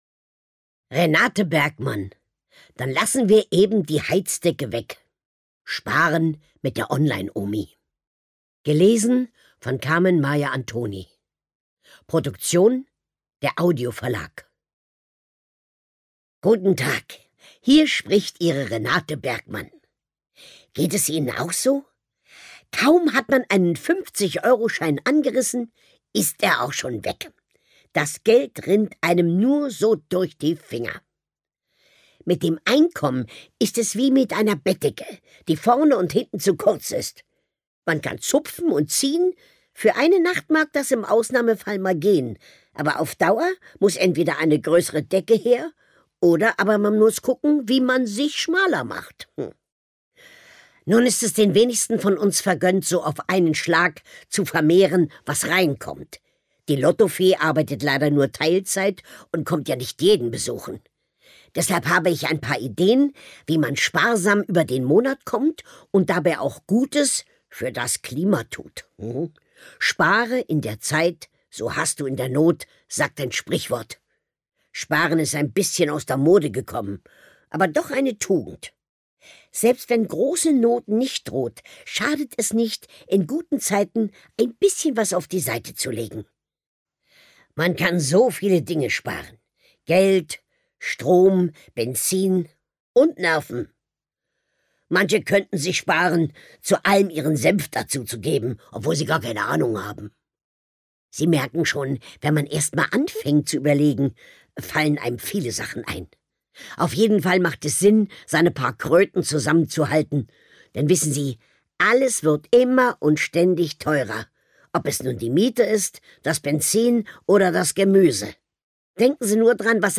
Carmen-Maja Antoni (Sprecher)
Ungekürzte Lesung